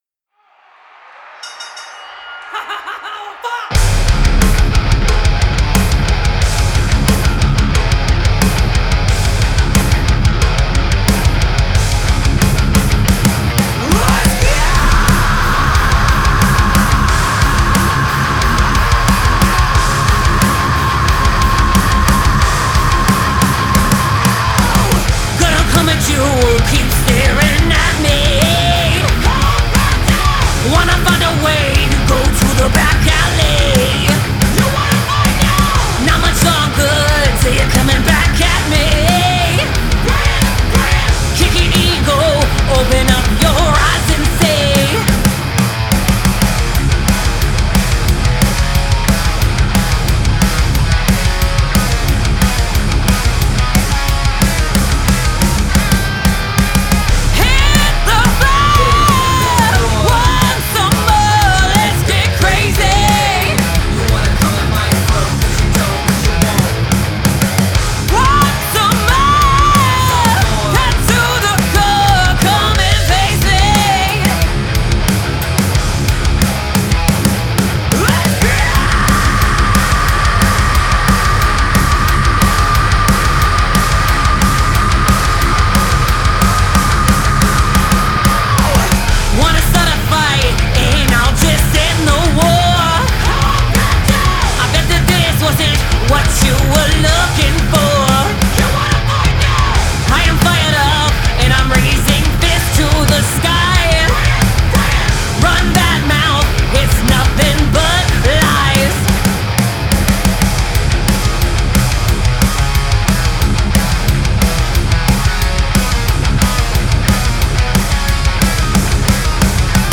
female-fronted hard rock and groove metal band
visceral riffs, anthemic hooks